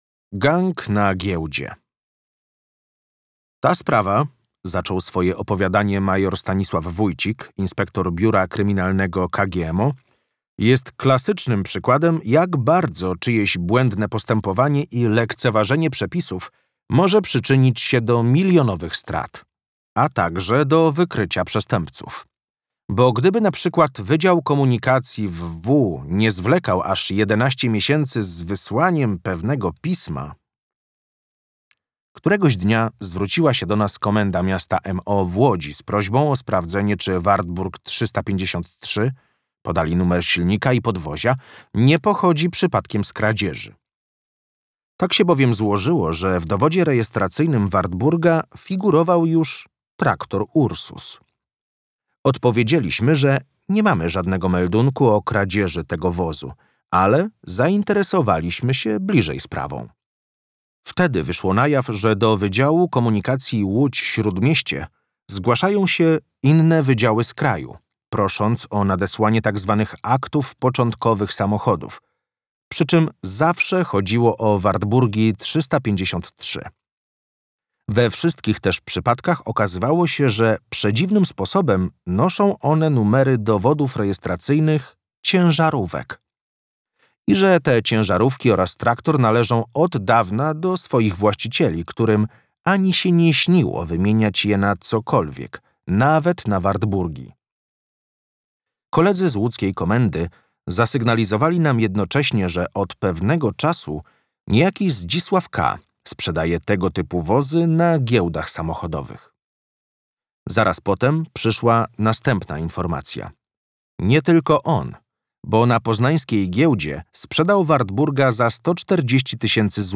DEMO AUDIOBOOK 2: